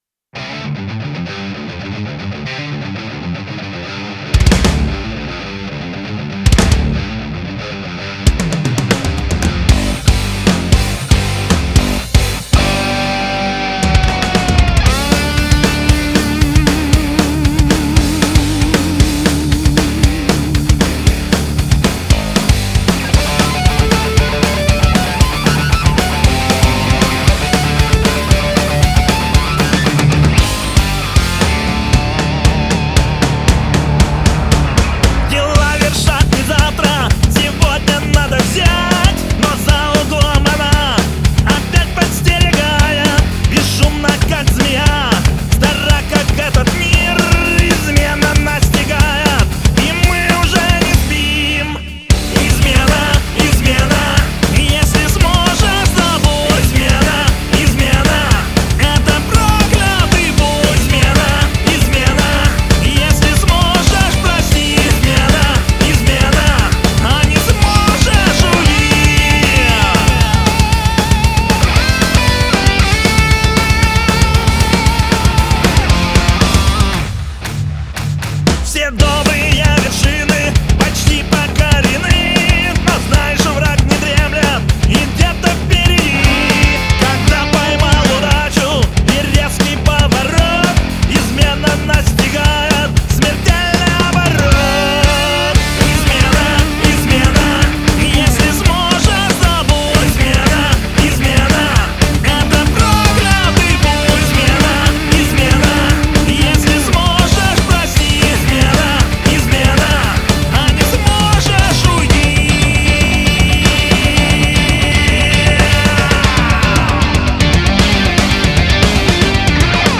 "Госизмена" Metal-Rock